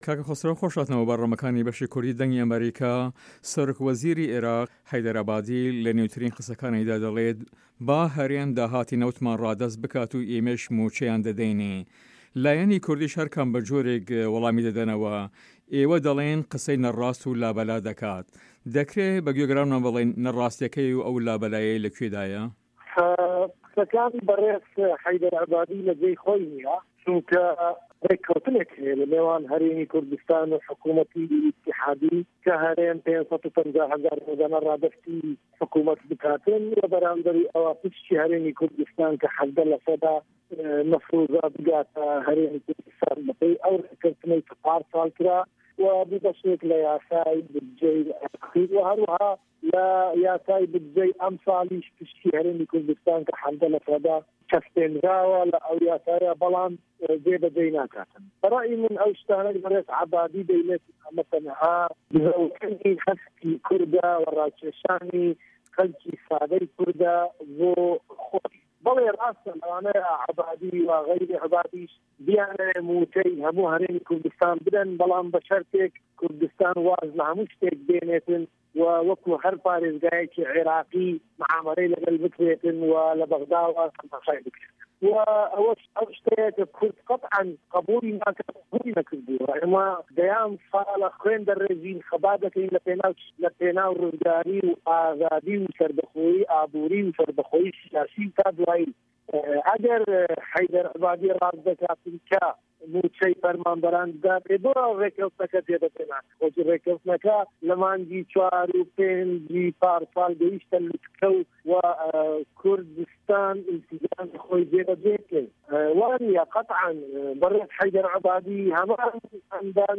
Interview with Goran Khosro